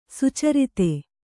♪ sucarite